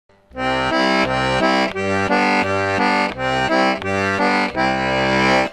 For a two-step, the left hand is generally played as: root-chord-root-chord
Two Step Bass Chords